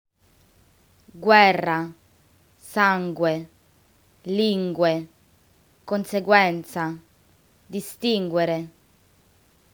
In Italian, G always gets a hard sound when followed by U. However, don’t forget to say the “U” as well!
In other words, “G” and “U” are pronounced as separate letters
ITALIAN WORDS WITH “GUE